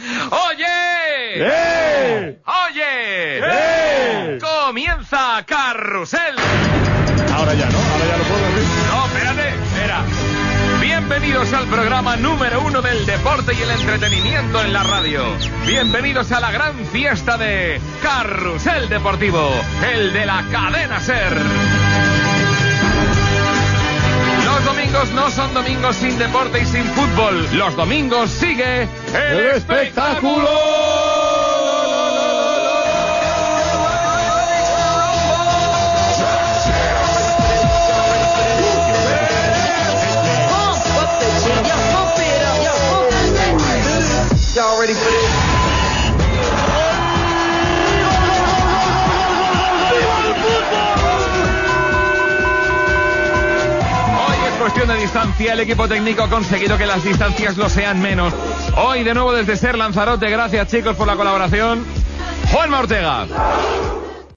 Presentació del programa
Esportiu
FM